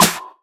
• Snare One Shot F Key 335.wav
Royality free snare sound tuned to the F note. Loudest frequency: 4257Hz
snare-one-shot-f-key-335-FKL.wav